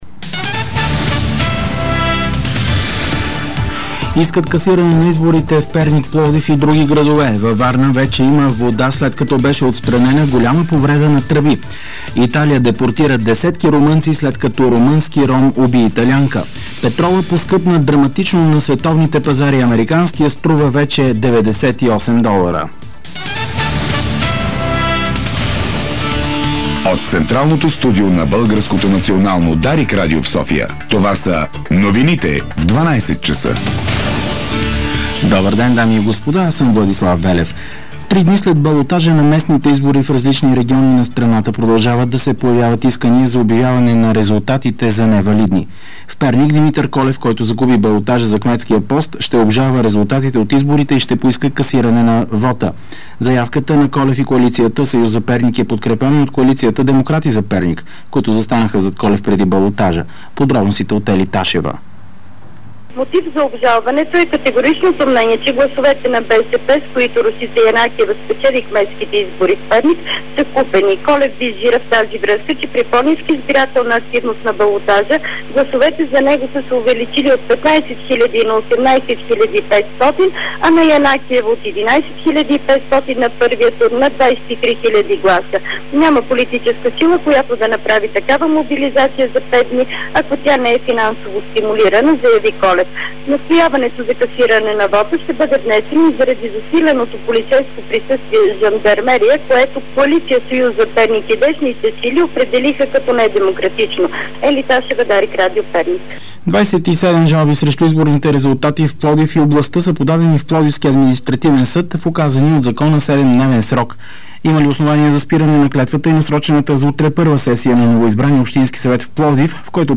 Обедна информационна емисия - 07.11.2007